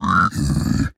Minecraft Version Minecraft Version snapshot Latest Release | Latest Snapshot snapshot / assets / minecraft / sounds / mob / piglin_brute / angry5.ogg Compare With Compare With Latest Release | Latest Snapshot
angry5.ogg